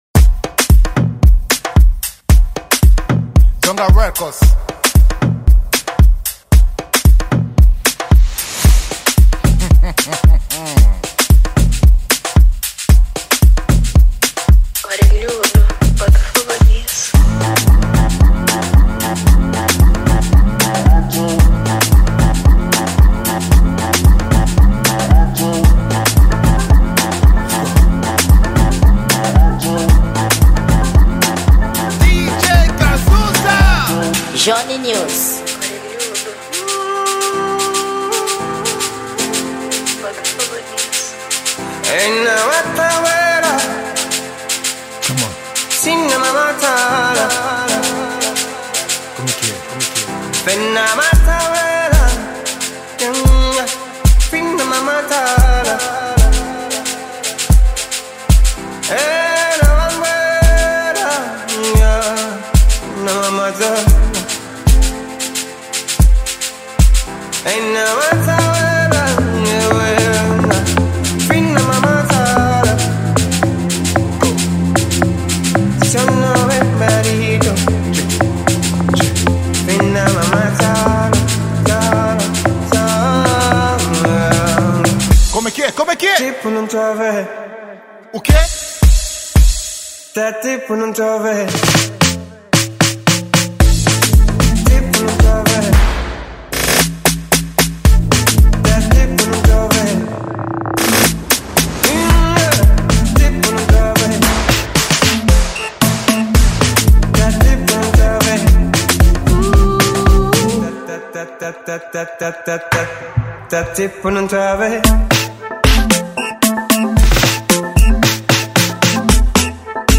Gênero: Amapiano